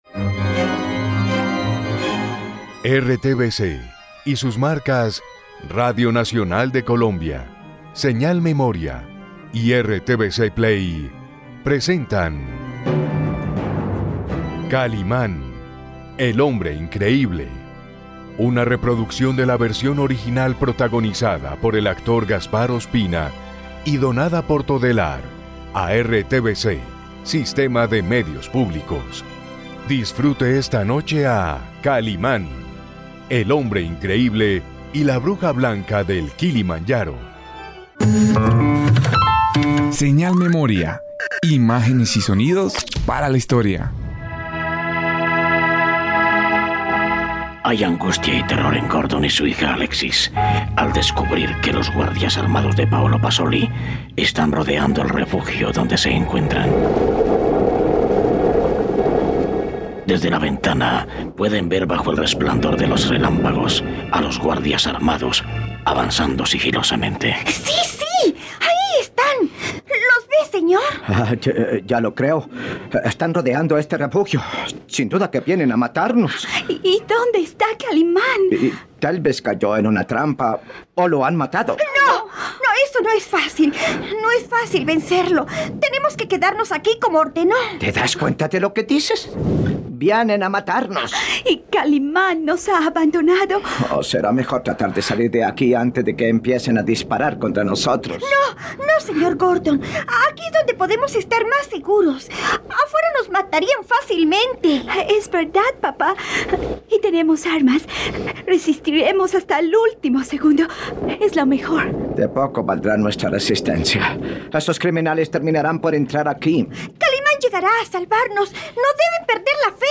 Radionovela.